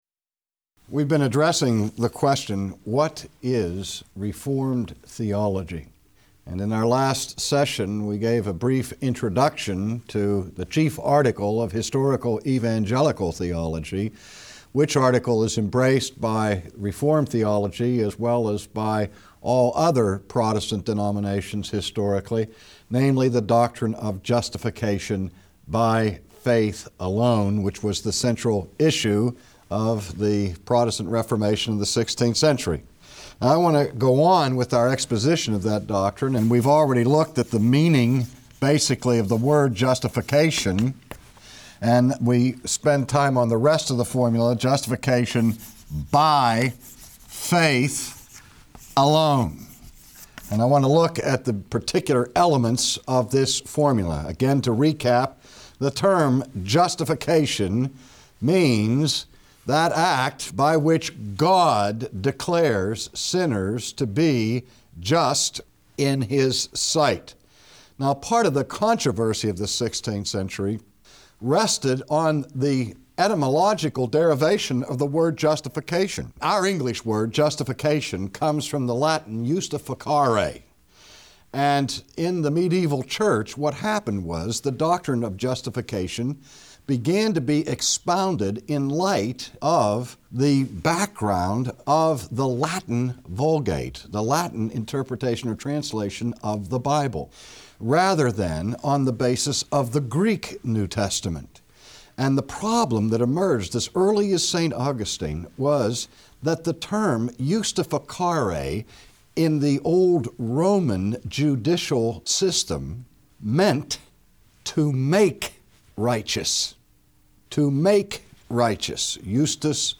Lecture 5, Faith Alone (Part 2) : The book of Romans says that we are altogether unrighteous, therefore the grave awaits us.